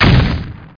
MagicMissileHit.mp3